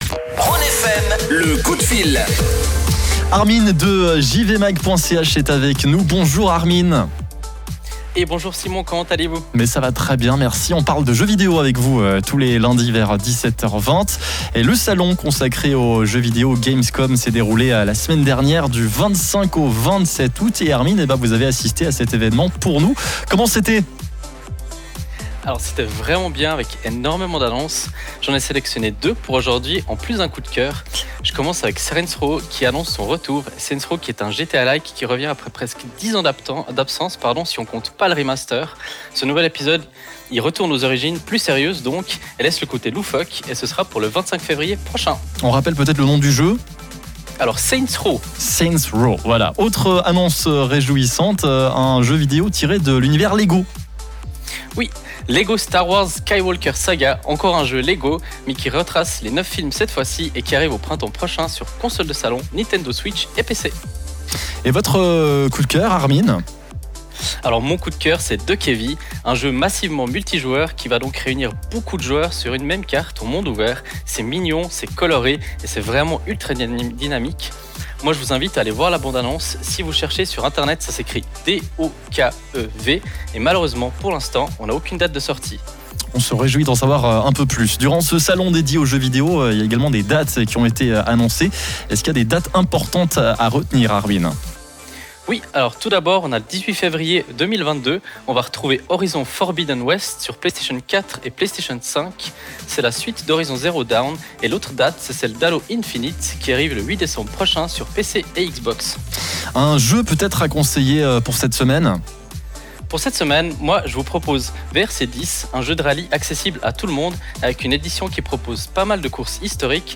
Aujourd’hui comme tous les lundis nous avons eu la chance de proposer une petite chronique gaming sur la radio Rhône FM ! C’était l’occasion de revenir sur trois jeux immanquables de cette Gamescom 21′, de parler de deux dates à retenir sans oublier de notre conseil de la semaine.